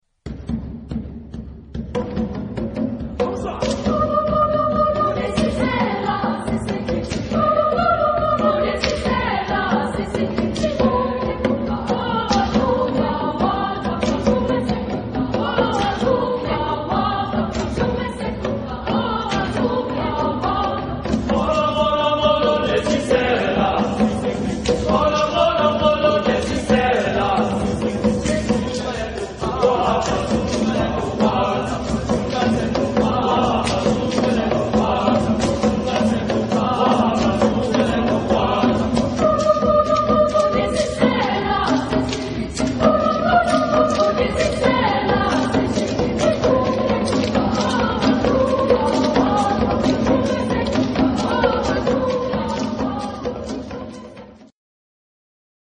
Work song
Genre-Style-Form: Work song ; Traditional ; Prayer
Mood of the piece: prayerful
Type of Choir: SA  (2 women voices )
Tonality: F major
sung by Drakensberg Boys' Choir